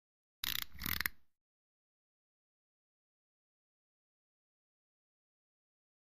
Misc. Sports Elements; Single Stopwatch Wind.